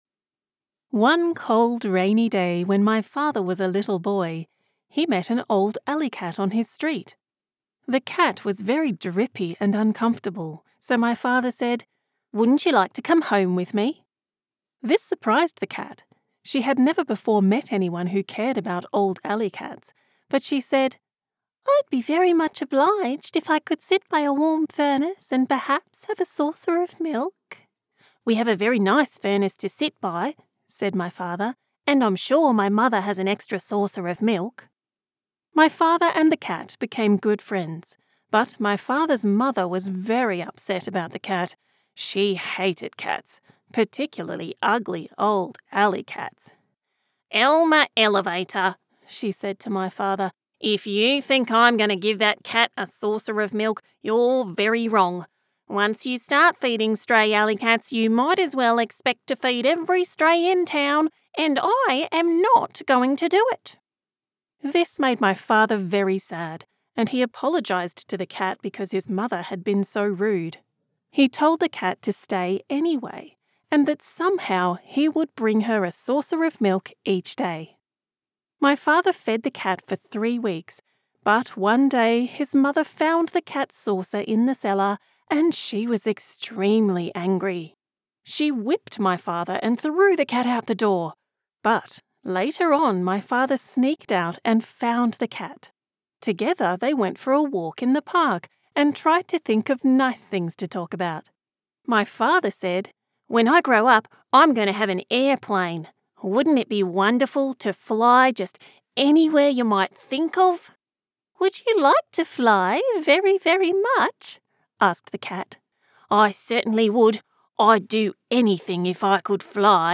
Female
Childrens Audiobook - Adventure
Words that describe my voice are Medical narration expert, Warm and comfortable, Authentic Australian.